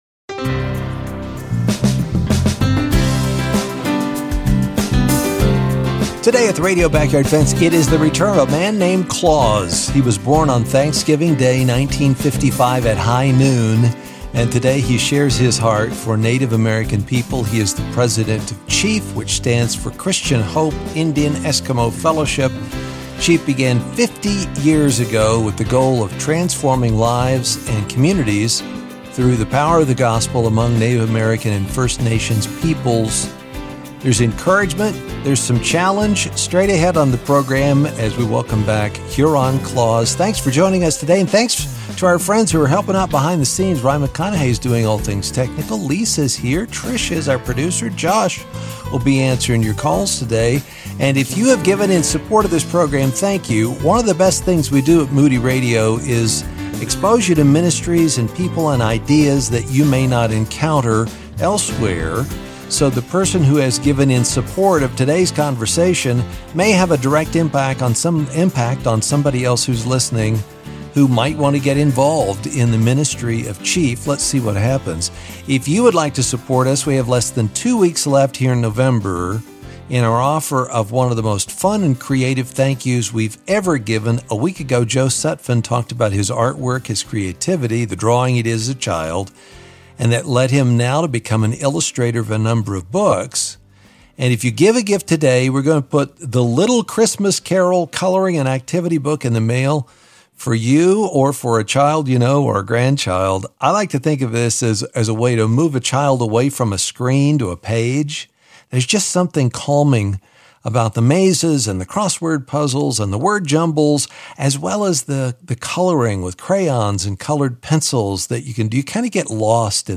Through engaging interviews and relatable insights, you’ll walk away encouraged, equipped, and inspired to live out your faith.